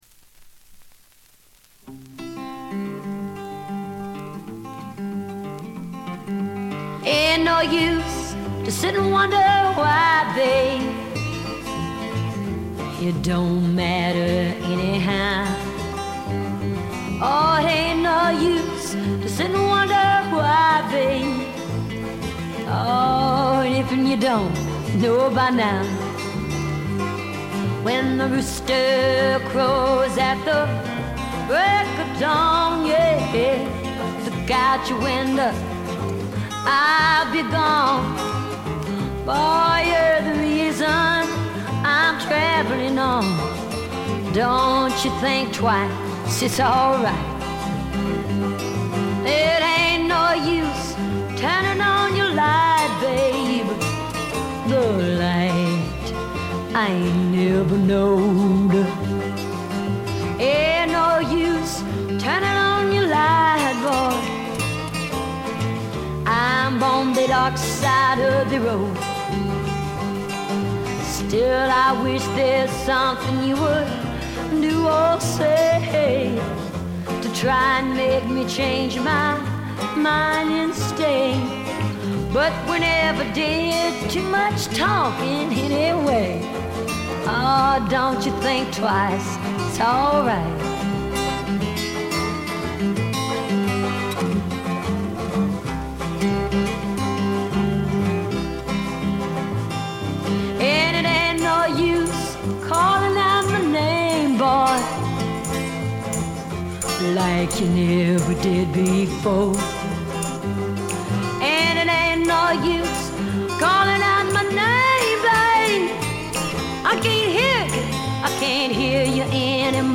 存在感ありまくりのヴォーカルが素晴らしいです。
試聴曲は現品からの取り込み音源です。